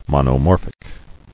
[mon·o·mor·phic]